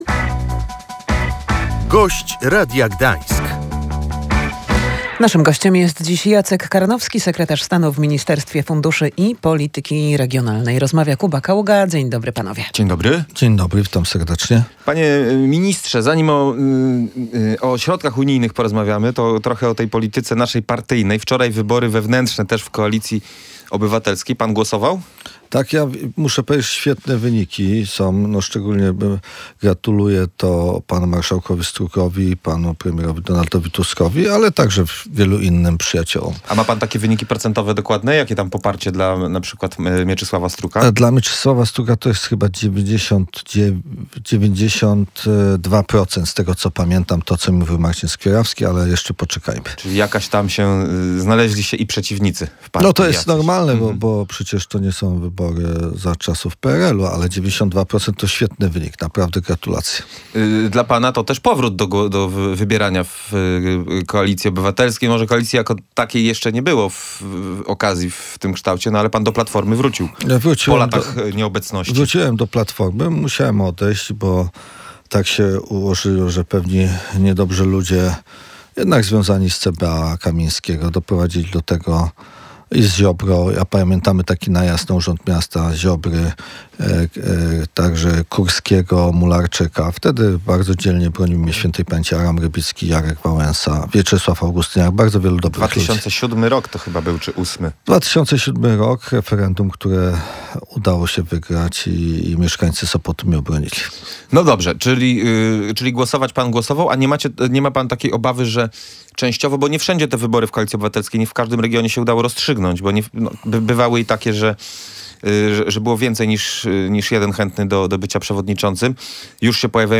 Ponad 90 procent poparcia dla Mieczysława Struka to ogromny mandat zaufania – tak wyniki szefów Koalicji Obywatelskiej w regionie skomentował w Radiu Gdańsk Jacek Karnowski, sekretarz stanu w Ministerstwie Funduszy i Polityki Regionalnej.